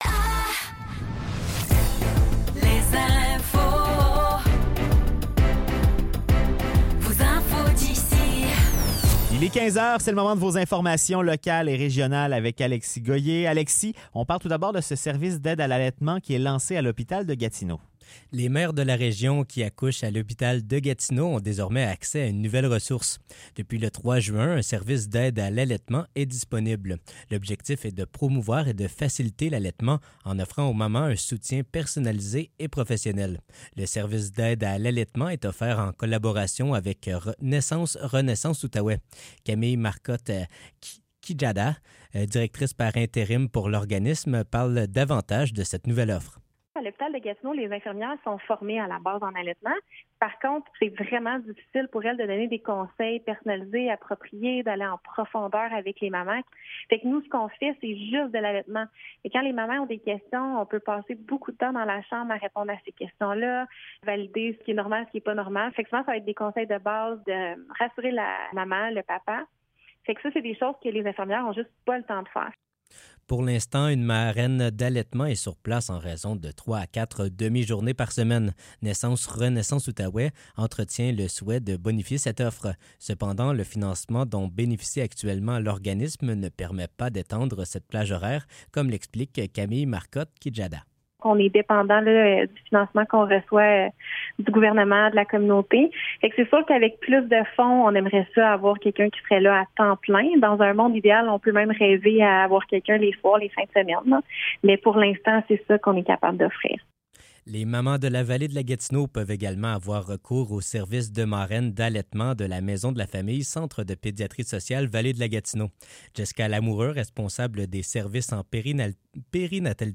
Nouvelles locales - 21 Août 2024 - 15 h